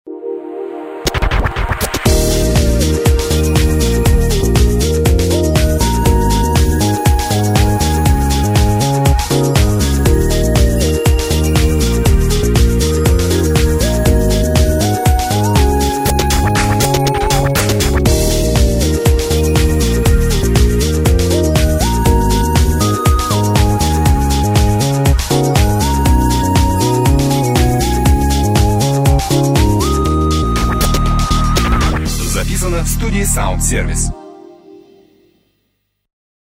Мелодия для мобильного телефона: